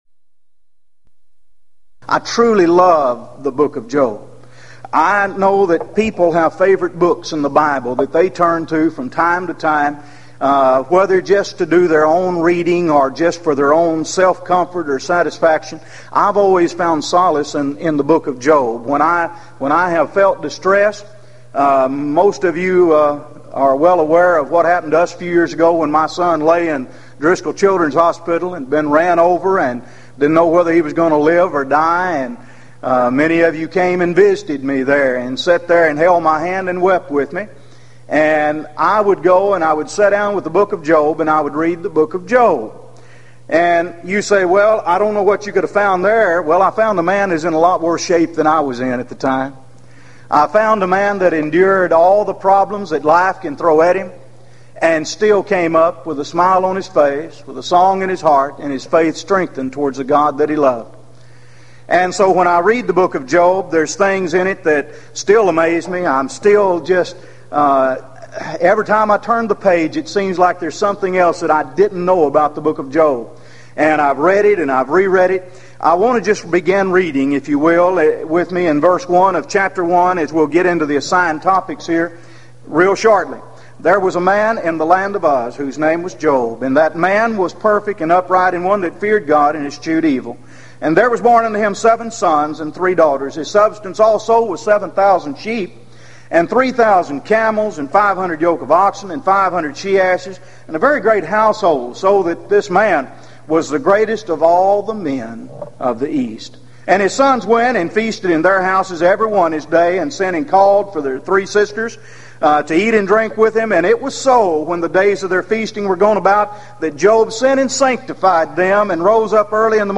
Event: 1995 Gulf Coast Lectures
lecture